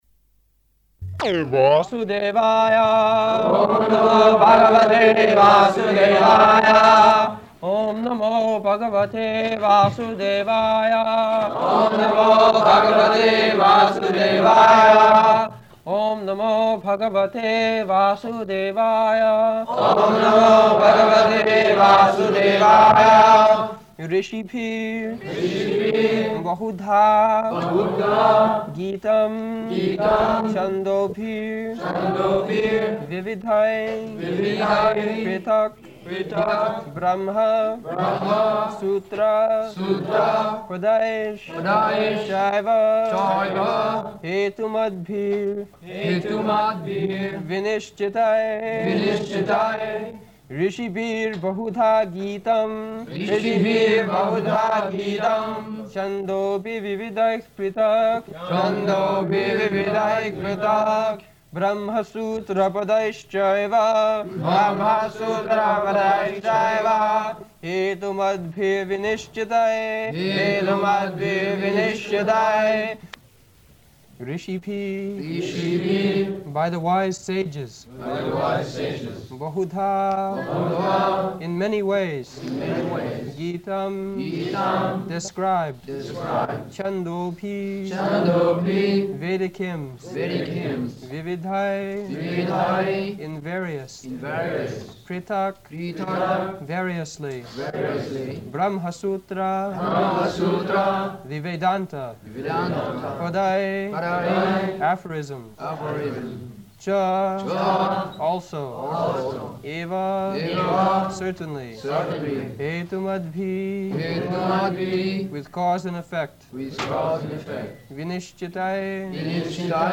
August 13th 1973 Location: Paris Audio file
[leads chanting of verse] [devotees repeat]